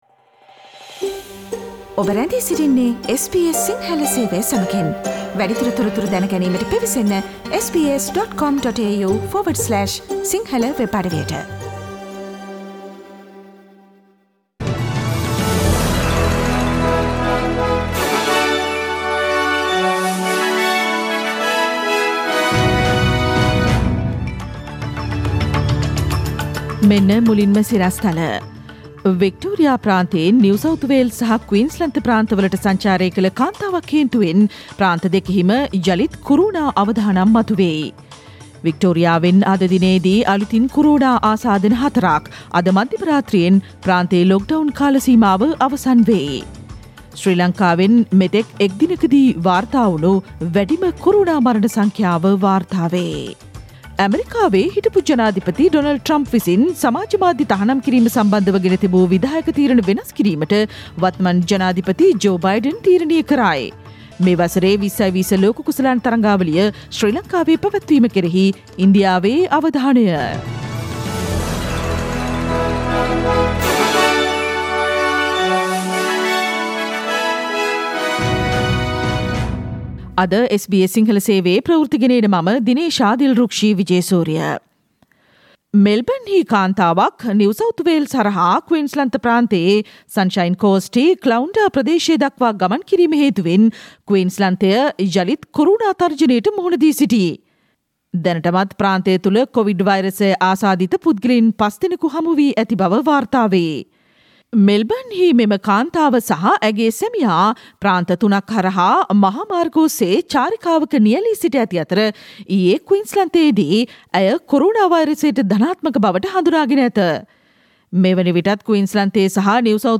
ඕස්ට්‍රේලියාවේ සහ ශ්‍රී ලංකාවේ අලුත්ම පුවත්, විදෙස් තොරතුරු සහ ක්‍රීඩා පුවත් රැගත් SBS සිංහල සේවයේ 2021 ජූනි මස 10 වන බ්‍රහස්පතින්දා වැඩසටහනේ ප්‍රවෘත්ති ප්‍රකාශයට සවන්දෙන්න